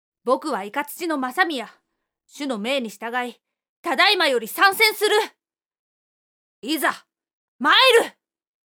【サンプルセリフ】